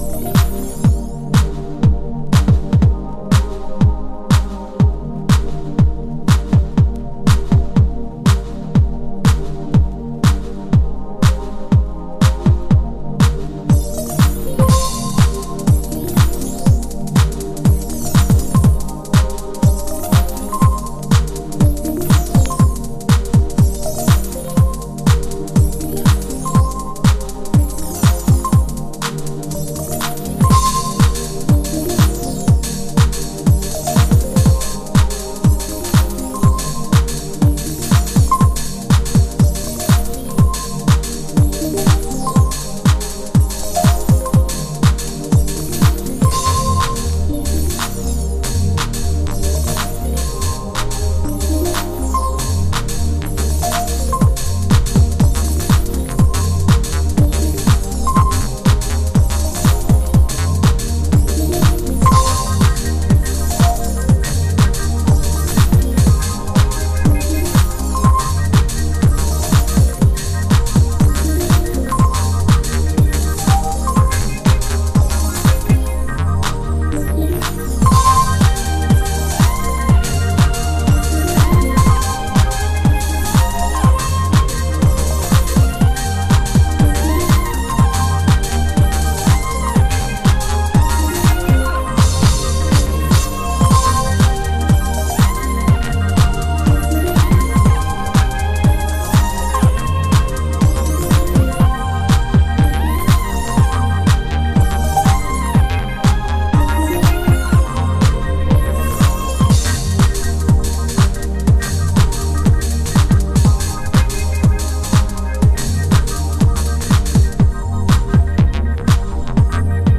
なんとも柔らかいビートメークとシルキーなシンセワーク、情景的なディープハウス。